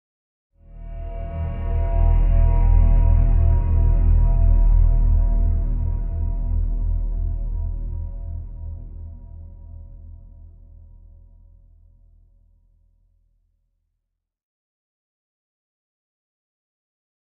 Digital Elongated Digital Foghorn with Bell Drone